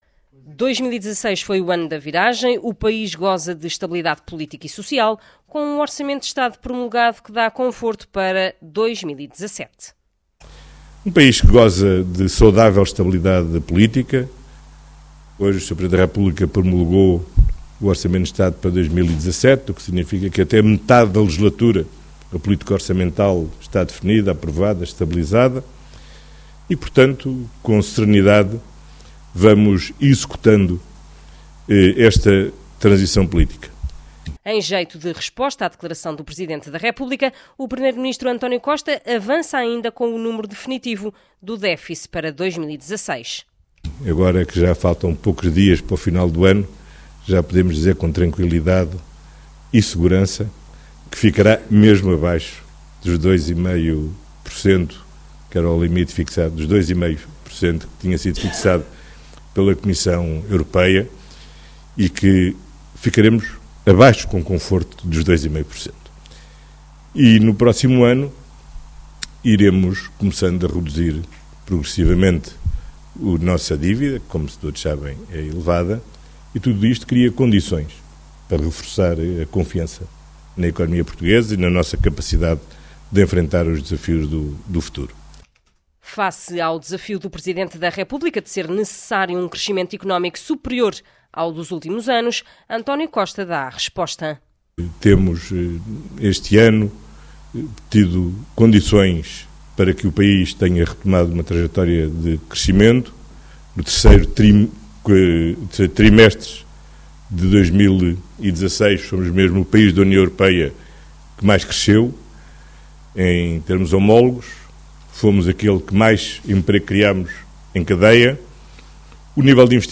António Costa falava perante os membros do Conselho da Diáspora das Comunidades Portuguesas, em São Bento, num discurso em que também adiantou que as exportações vão crescer 6% em 2016.
Reportagem